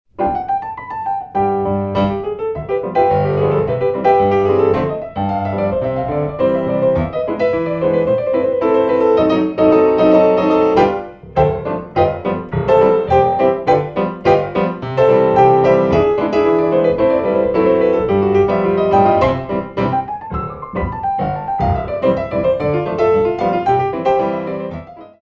Petits Battement sur le Cou de Pied